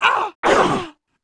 Index of /App/sound/monster/ice_snow_witch
fall_1.wav